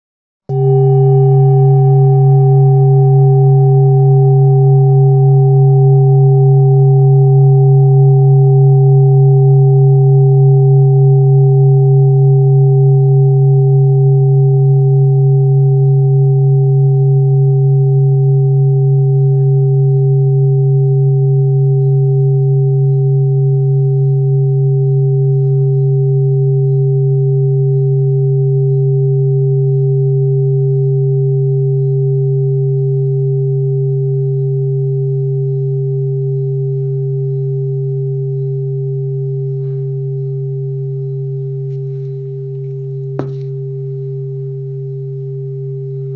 Base Chakra High Quality Singing Bowl with Tibetan Medical Man, Select Accessories , A bowl used for meditation and healing, producing a soothing sound that promotes relaxation and mindfulness
Material 7 Metal Bronze
Singing Bowl
Tibetan bowls emit very pure tones, close to sine waves.
Like a bell, the tone is produced by striking the side of the bowl with a wooden mallet.